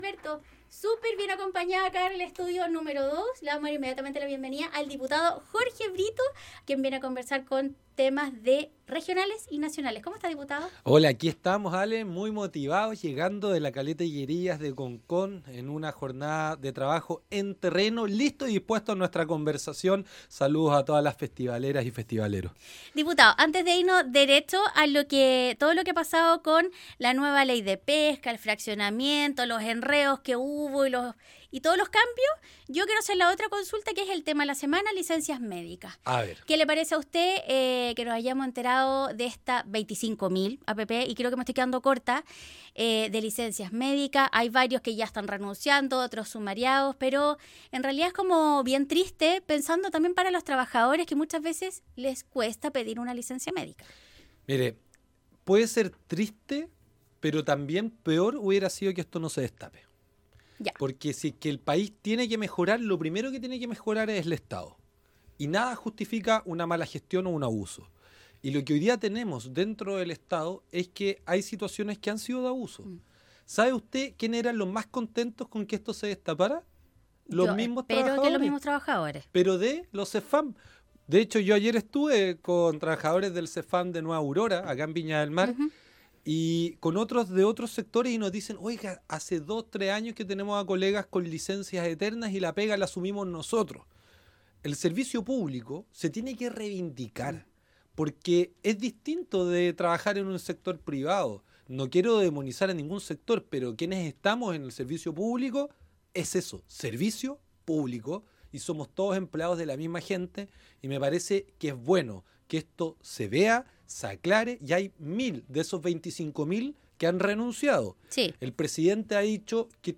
El Diputado del Distrito 7 conversó con Radio Festival sobre el fraccionamiento pesquero, las licencias médicas y como espera el discurso Presidencial.